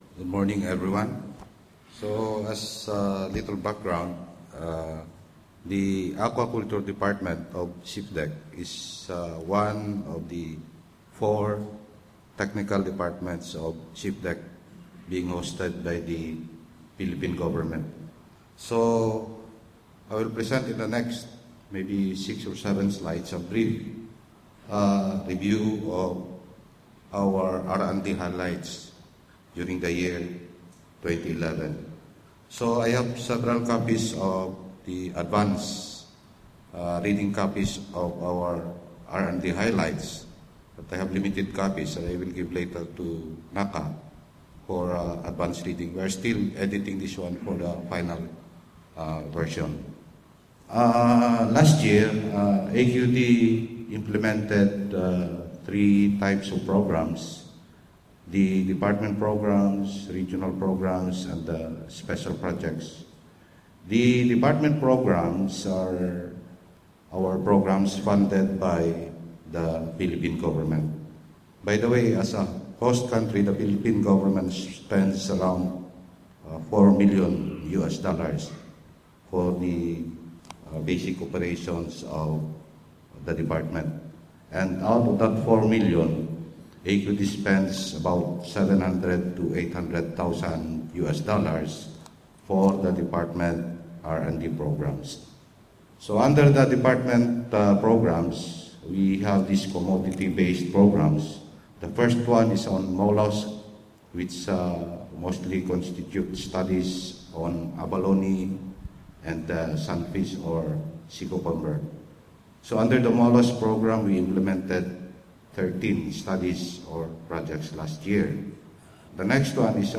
The presentations were made at the 23rd NACA Governing Council Meeting, which was held in Siem Reap, 27-29 May 2011, hosted by the Government of Cambodia.